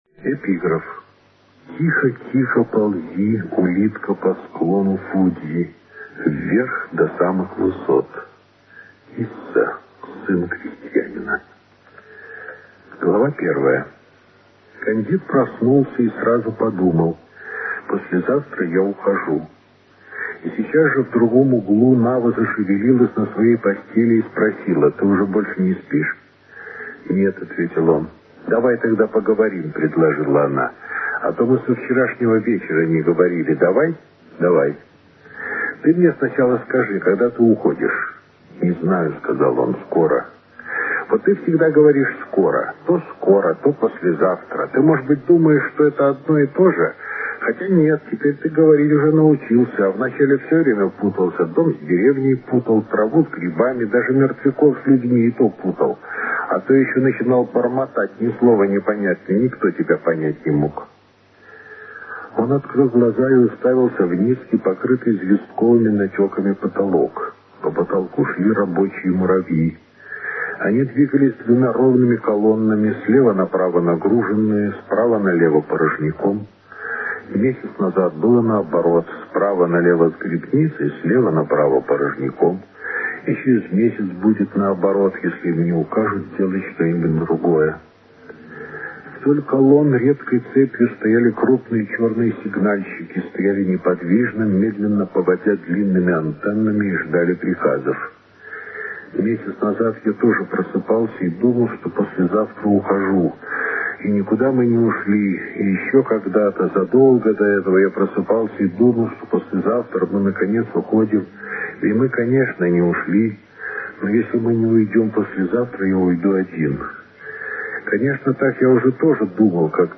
Борис Стругацкий читает главы «Улитки на склоне»: 2 мин. (401 Кб);